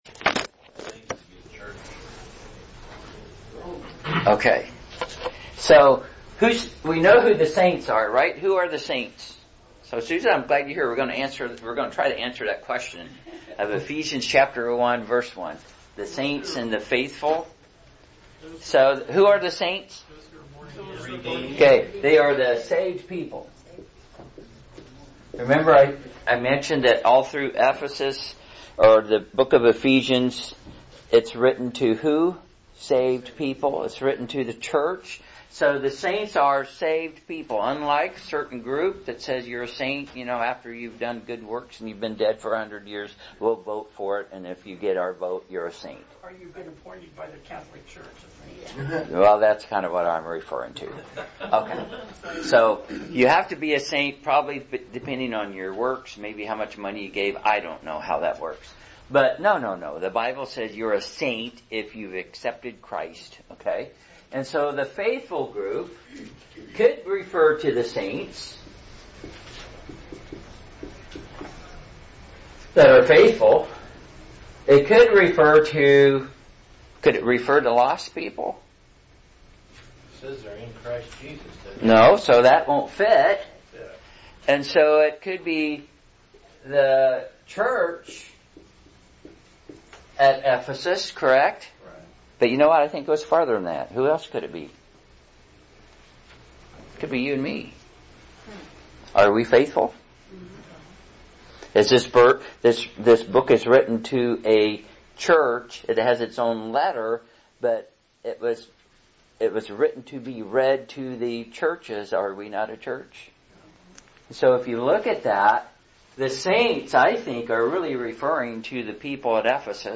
Download Download Passage Ephesians 1:1 Podcasts Find a link here to subscribe to any or all of the Podcasts avaiable Basic Doctrine Current Sermon Basic Doctrine lesson 13: Who Are the Saints & the Faithful?